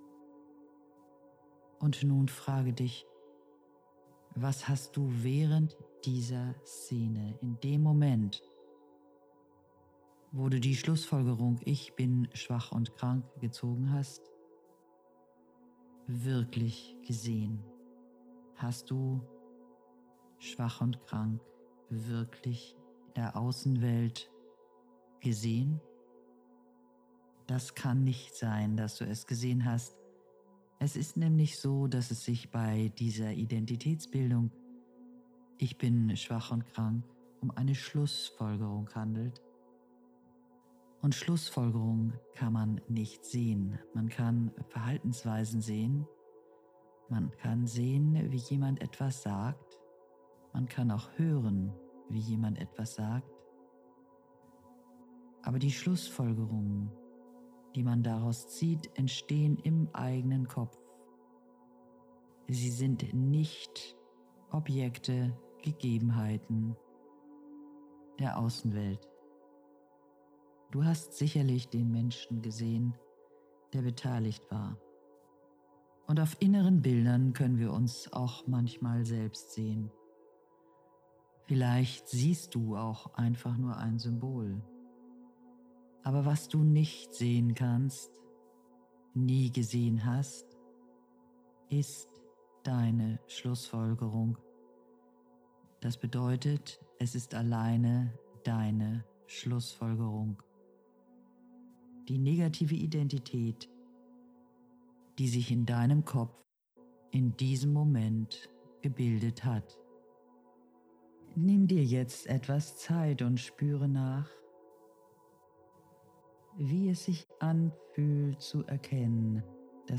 Meditation zum Buch: